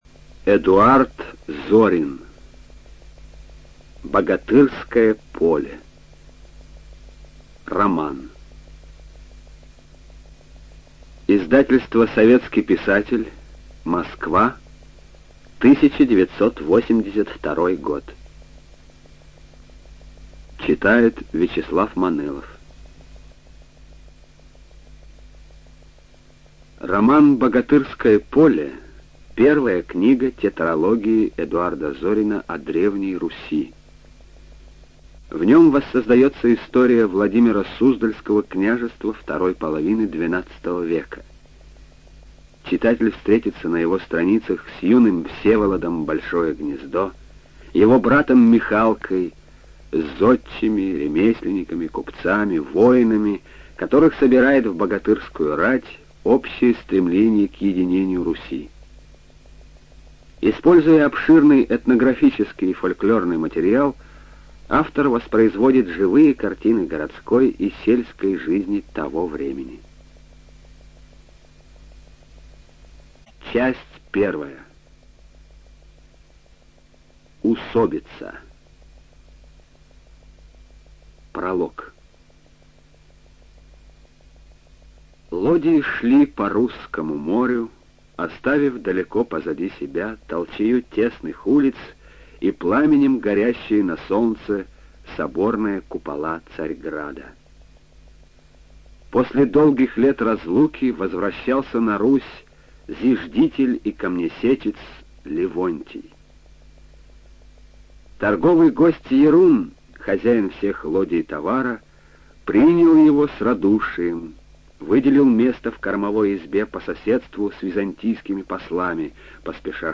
Студия звукозаписиРеспубликанский дом звукозаписи и печати УТОС